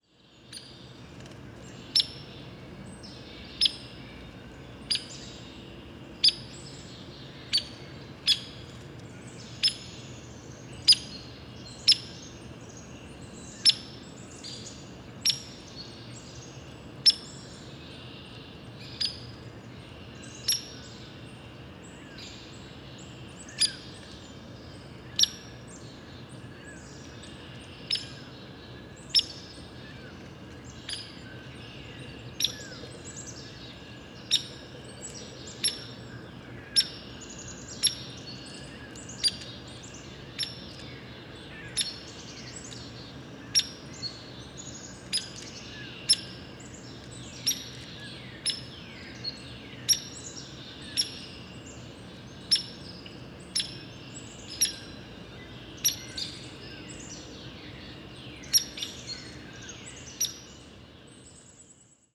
Pic chevelu – Dryobates villosus
Cri ‘Peek’ Île des Soeurs, Montréal, QC, 45°27’19.6″N 73°33’12.6″W. 13 avril 2019. matin.